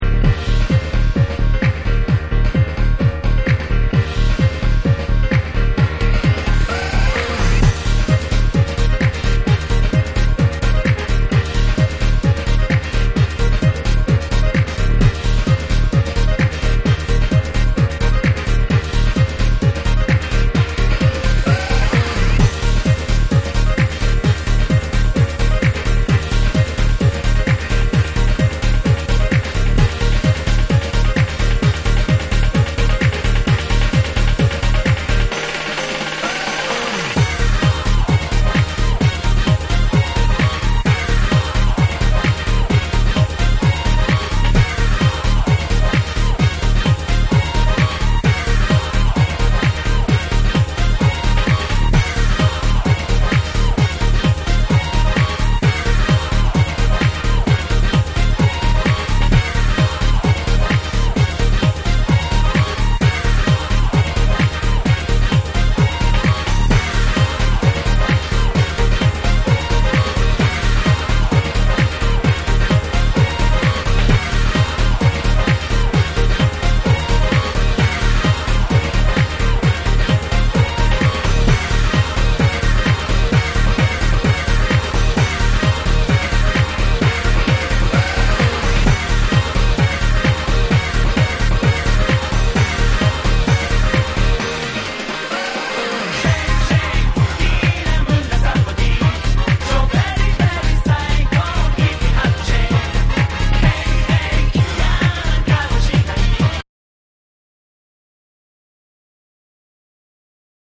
JAPANESE / TECHNO & HOUSE